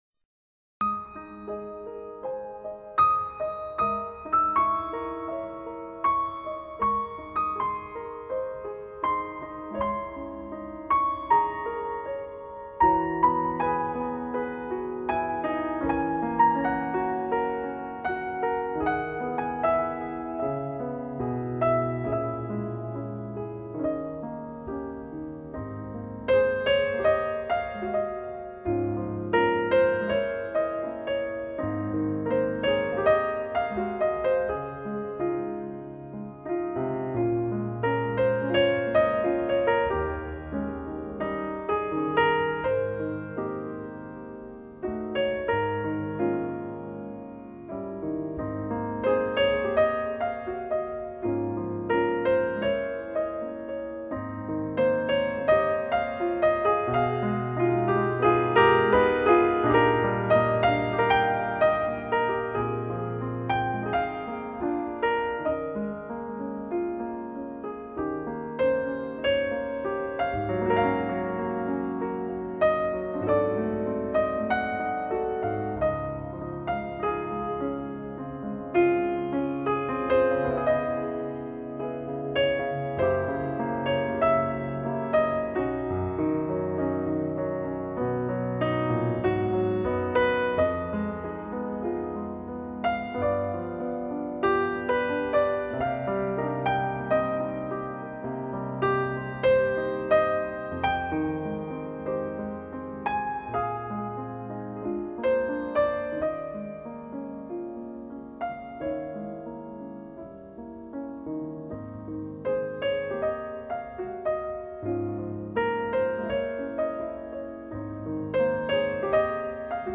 类　别： NewAge
清澈而没有过多的粘稠，温柔却又溪水长流。
更加入柔情的小提琴和单簧管伴奏，听起来非常温暖和舒适。
在清幽的音乐氛围里，清澈的钢琴音色，恰如其分地妆点出绮丽的光影，
融合大提琴与小提琴婉约动人的低诉，形成比例完美的弦乐合奏，